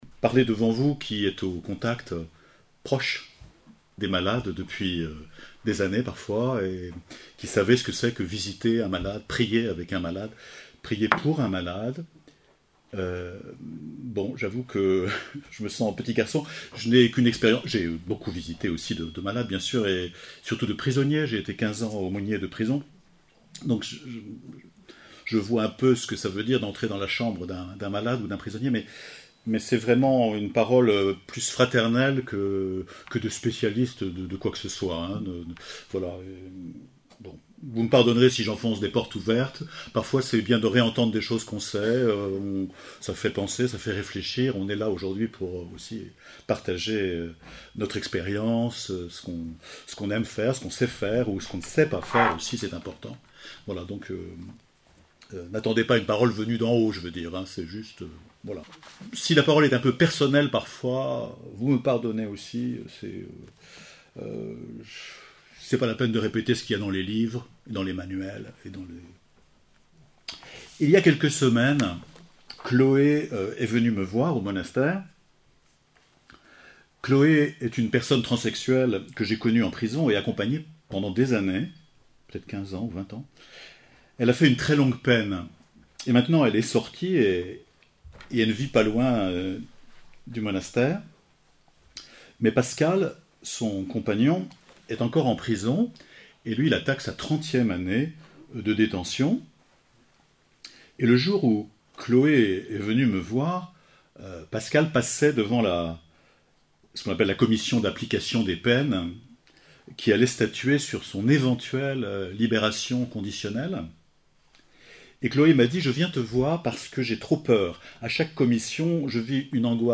Enregistrée le 12/10/2024 à l’abbaye de Belloc lors de la Journée diocésaine de la Pastorale de la Santé.